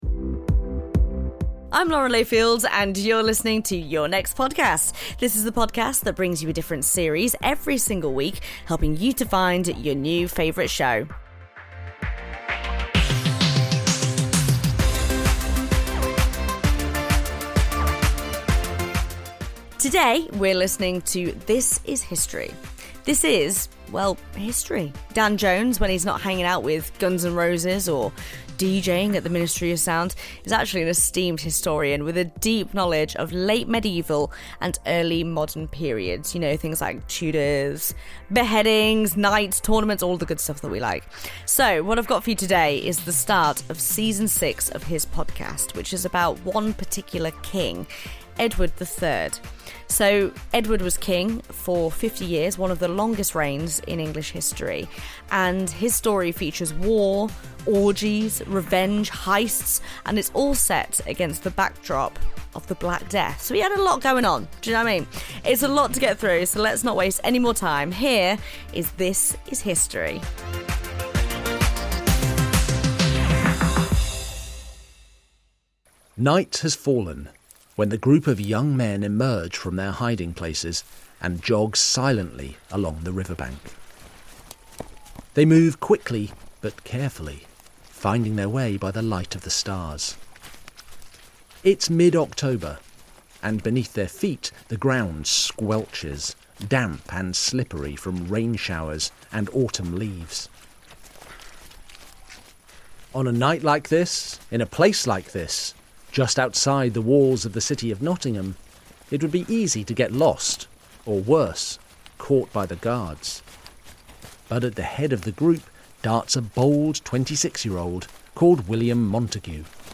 Lauren Layfield introduces This is History on the series recommendation show Your Next Podcast. Hosted by the New York Times best-selling author and historian Dan Jones, season six of This Is History immerses listeners into the thick of one of the most turbulent eras of the Middle Ages and the rise of one of England’s most formidable kings, Edward III. From naval warfare and deadly military inventions to orgies, and castle heists, all set in the backdrop of the Black Death, season six of This Is History is full of explosive moments as Dan unfolds the saga of history’s deadliest dynasty.